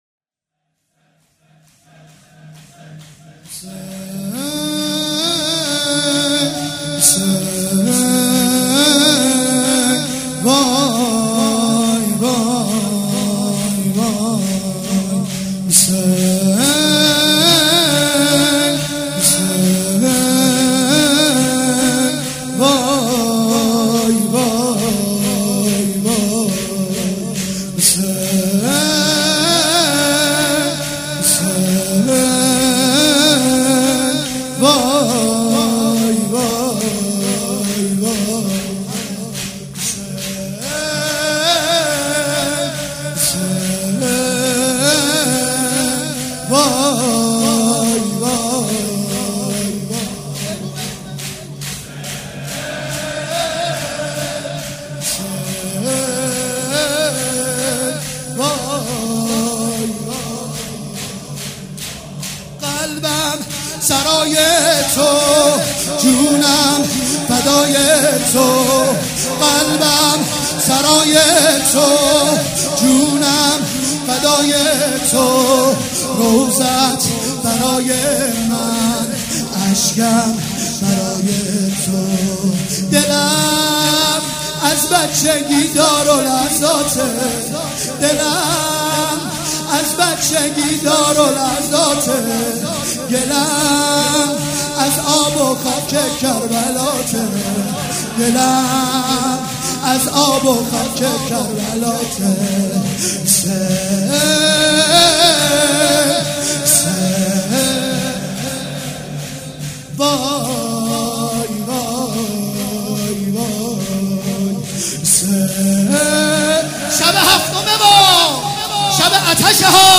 شب هفتم محرم الحرام‌ شنبه ۱7 مهرماه ۱۳۹۵ هيئت ريحانة الحسين(س)
سبک اثــر شور
مراسم عزاداری شب هفتم